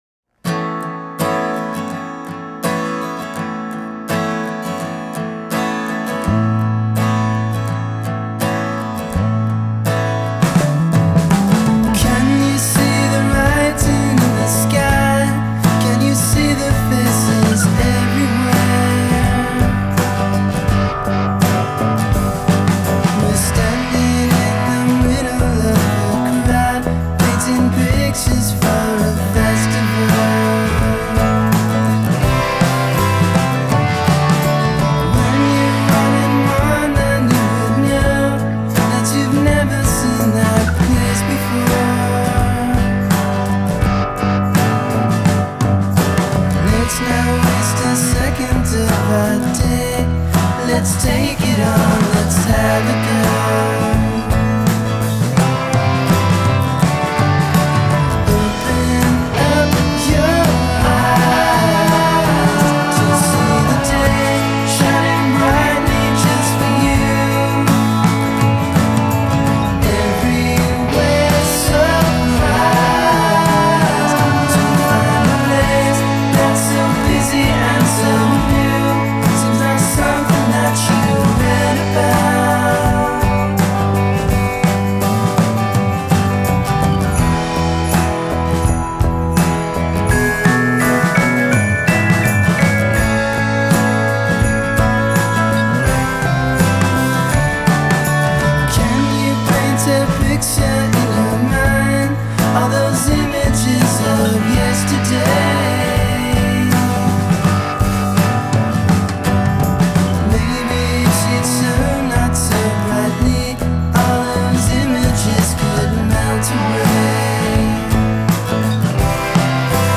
mellow psychedelic pop